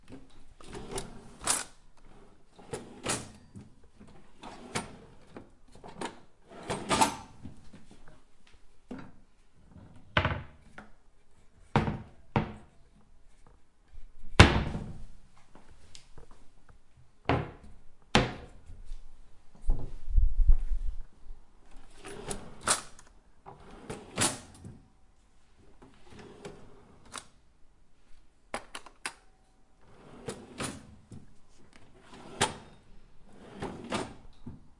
厨房抽屉
描述：厨房抽屉：打开和关闭不同厨房抽屉的各种声音，其中一些带有餐具 录音机：放大H4N，内置麦克风，48kHz，24bit
Tag: 厨房 打开 关闭 关闭 大满贯 抽屉 橱柜 处理